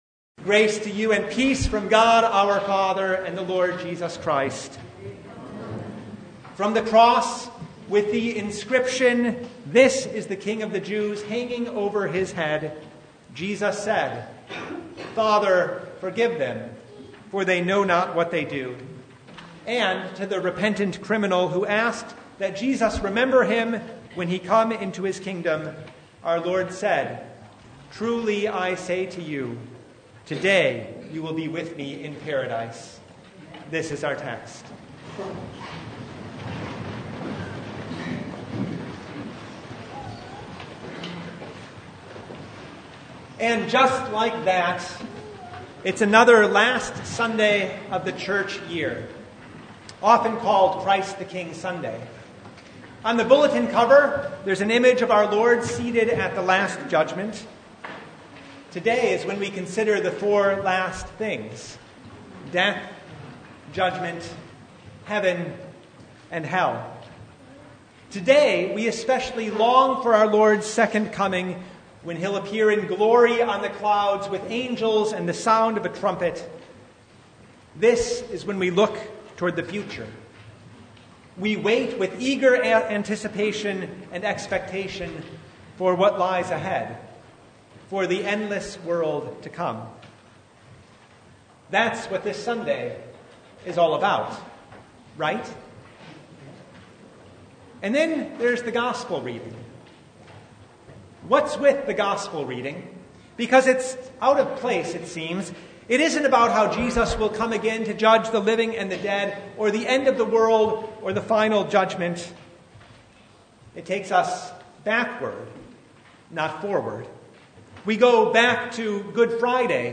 Topics: Sermon Only « Look Up!